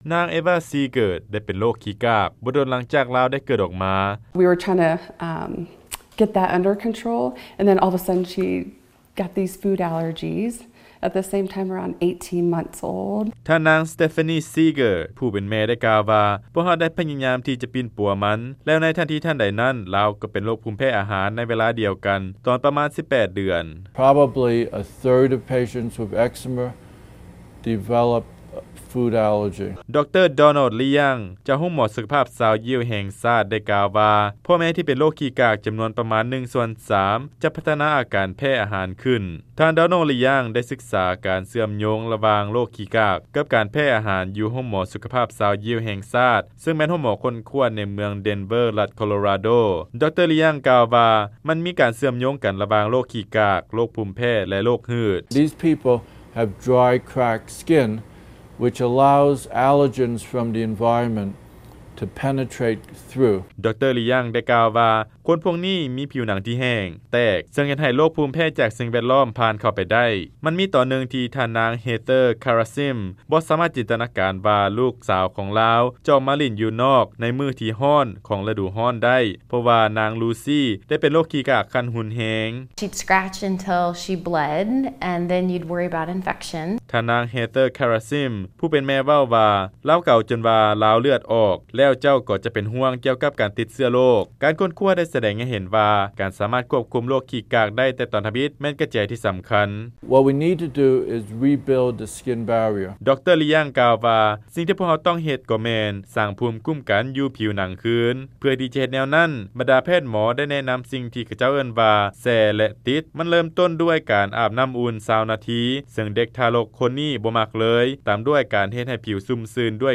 ຟັງລາຍງານ ການປິ່ນປົວ ໂຣກຂີ້ກາກໃນໄວເດັກ ຈະຊ່ວຍປ້ອງກັນ ການເປັນໂຣກຫືດ ແລະ ໂຣກພູມແພ້ຕ່າງໆ